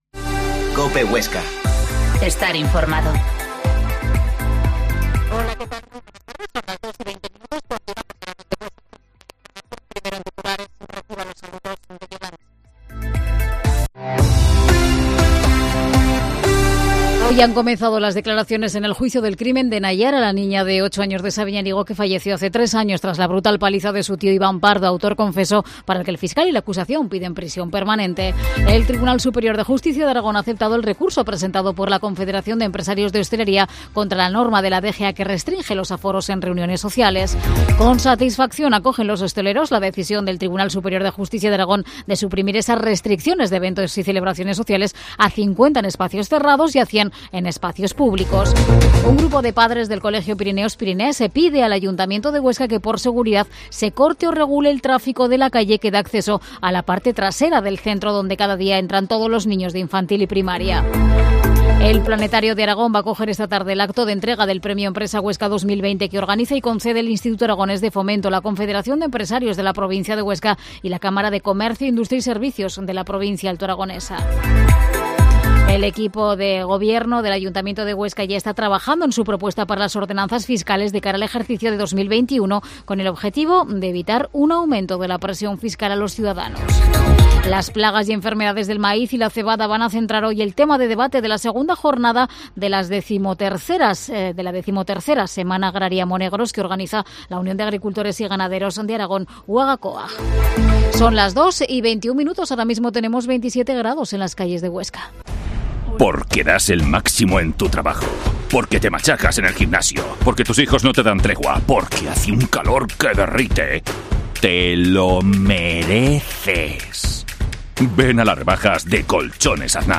Informativo Mediodía en Huesca